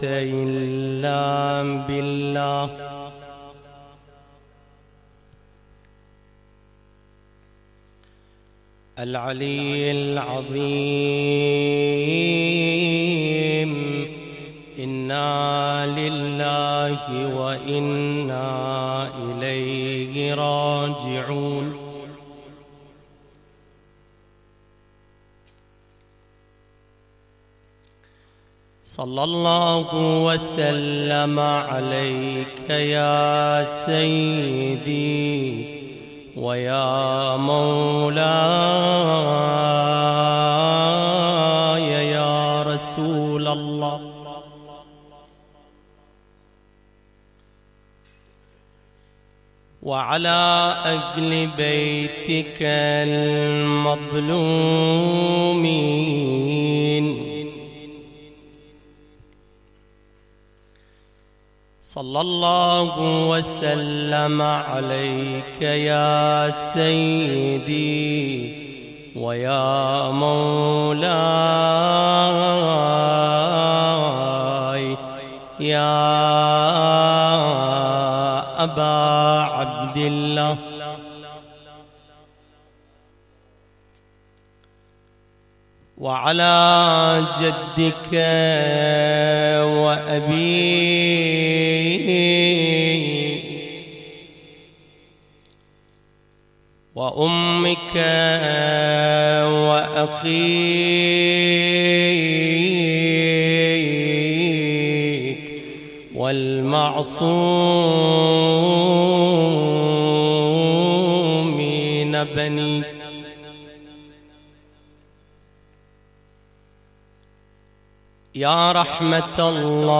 تغطية صوتية: المجلس الحسيني ليلة 6 رمضان 1439هـ بمشاركة الخطيب